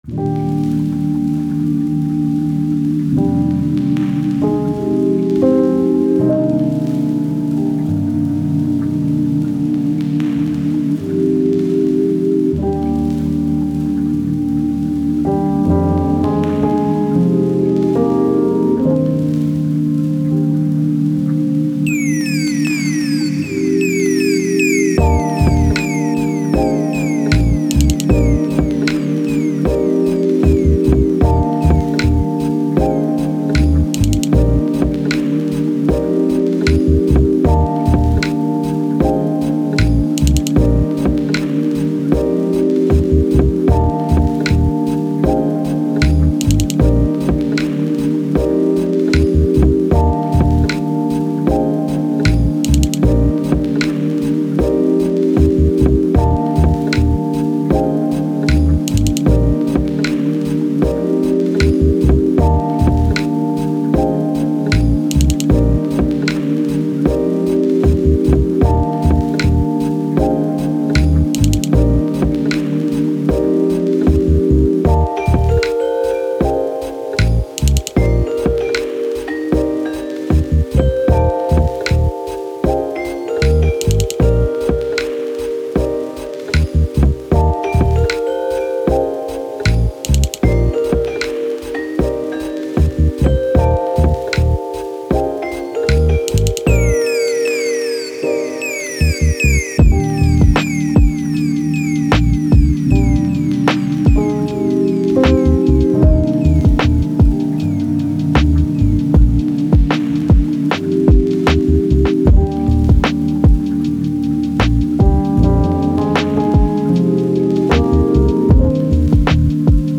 Chill Hop, Lofi, Mix, Chill, Sleep, Study